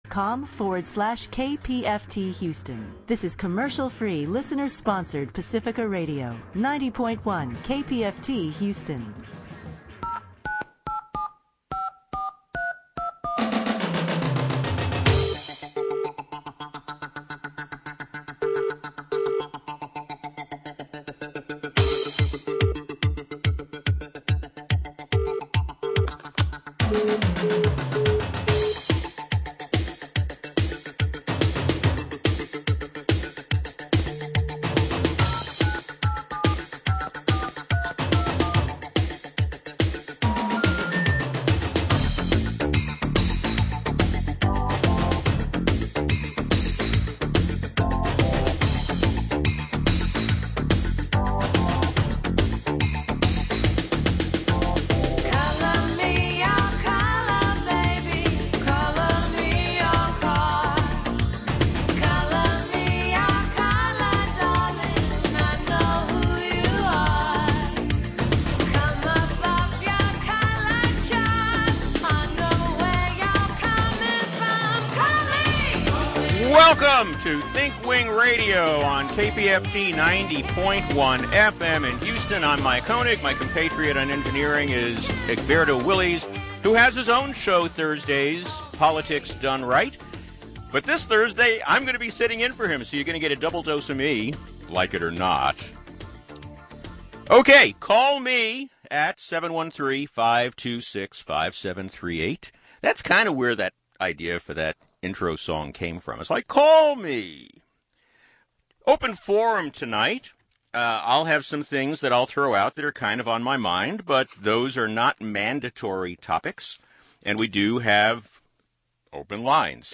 Listen live on the radio or on the internet from anywhere in the world!